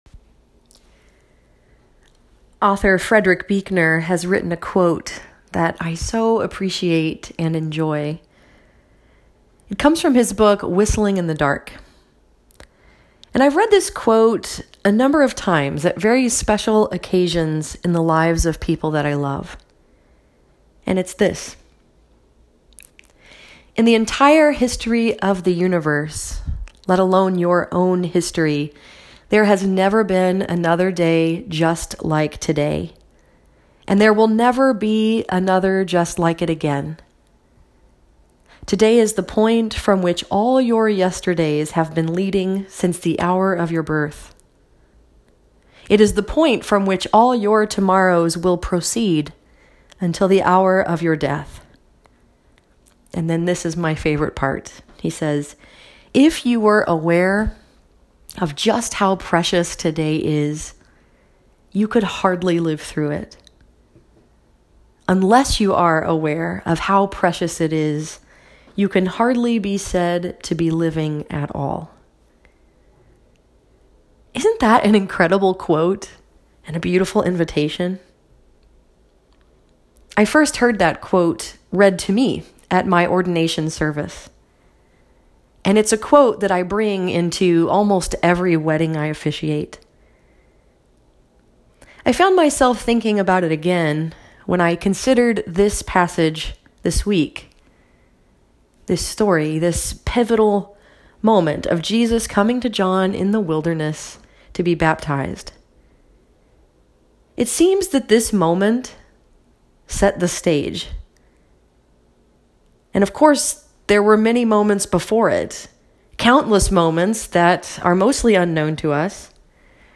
This sermon was preached at Northside Presbyterian Church in Ann Arbor, Michigan and was focused upon the story told in Matthew 3:1-13.